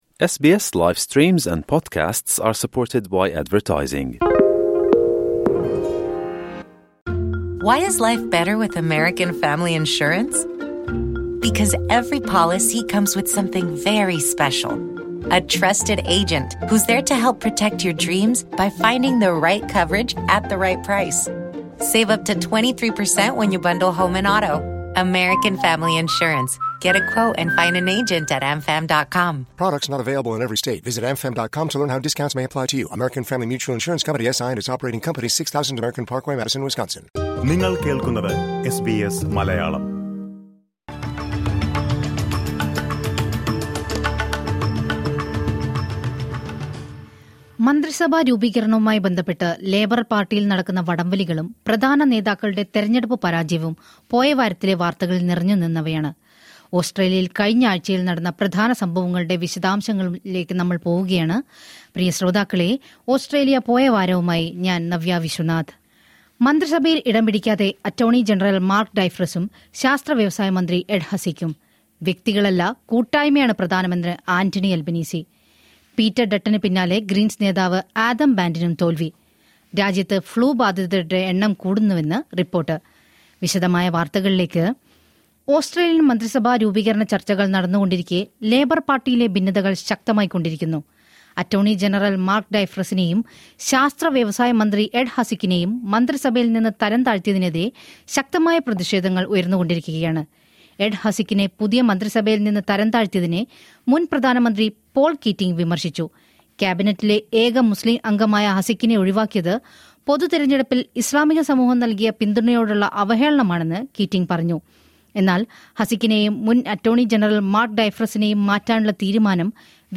ഇക്കഴിഞ്ഞയാഴ്ചയിലെ ഓസ്‌ട്രേലിയയിലെ ഏറ്റവും പ്രധാന വാര്‍ത്തകള്‍ ചുരുക്കത്തില്‍...